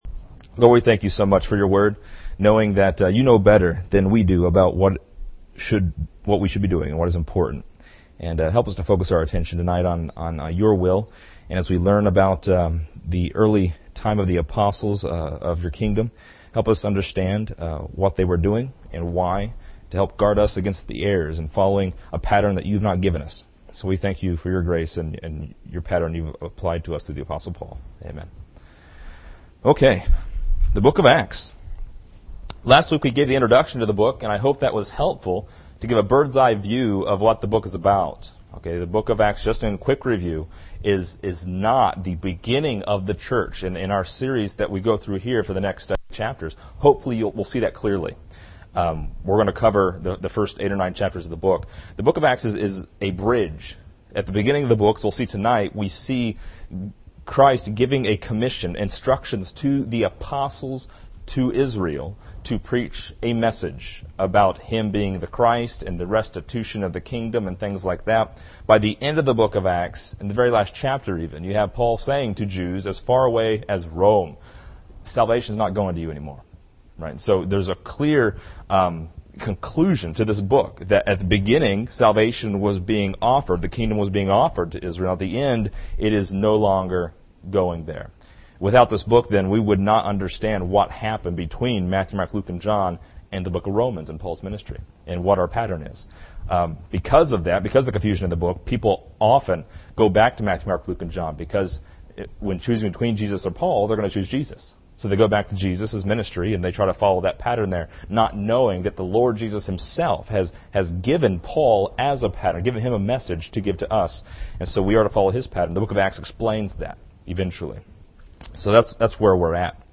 This lesson is part 02 in a verse by verse study through Acts titled: The Jerusalem Commission.